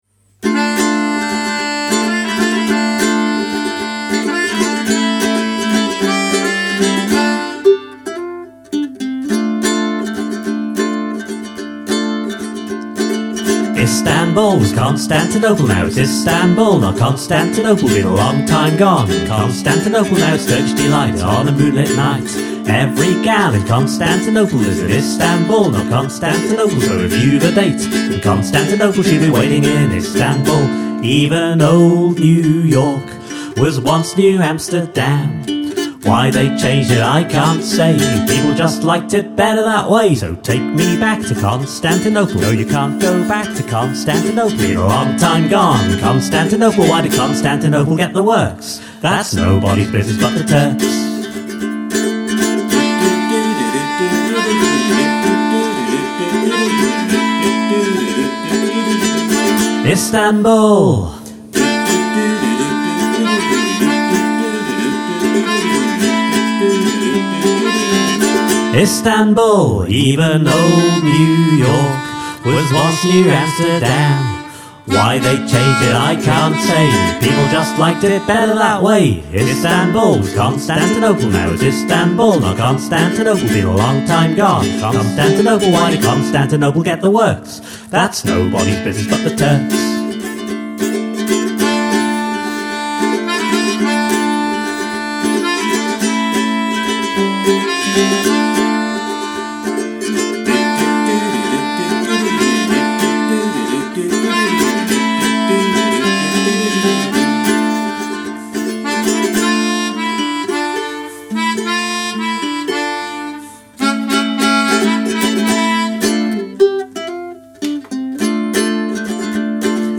Still added some melodica though.